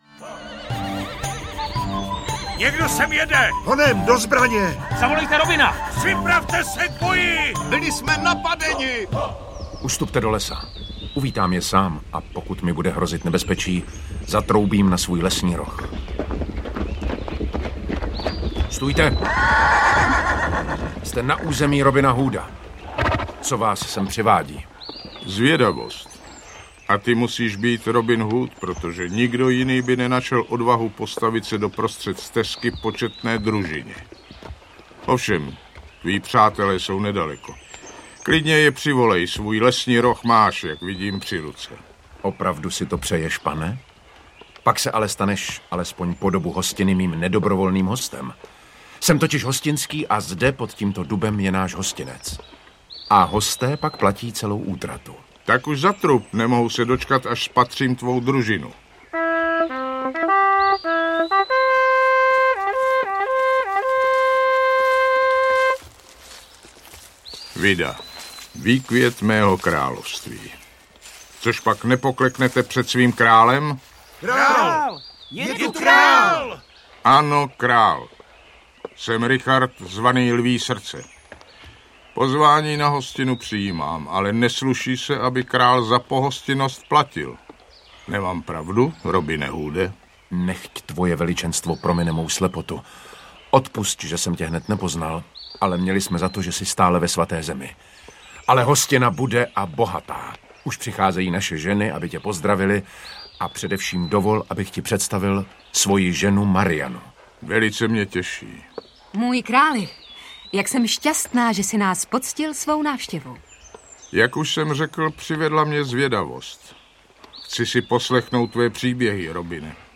Robin Hood audiokniha
Audiokniha Robin Hood - dramatizace jednoho z nejslavnějších dobrodružných románů všech dob!
Ukázka z knihy